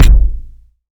KICK.3.NEPT.wav